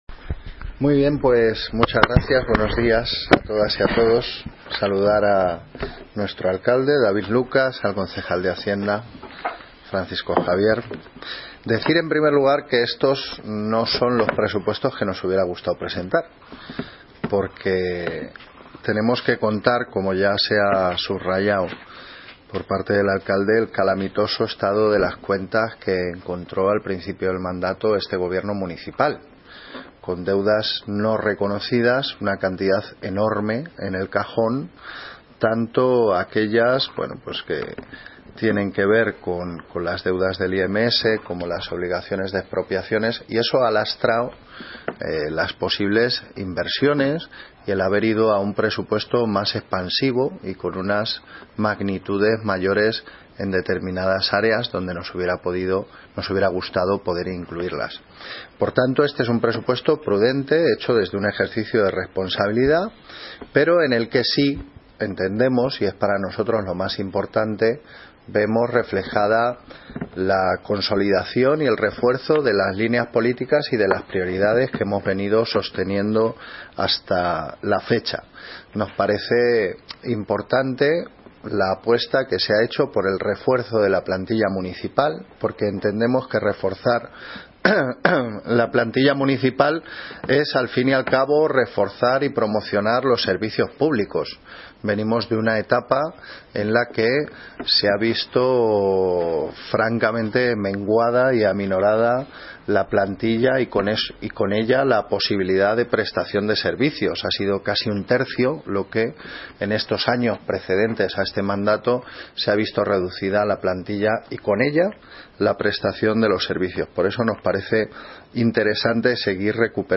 Audio - Gabriel Ortega (Concejal de Cultura y Bienestar Social) Presentación Presupuestos 2018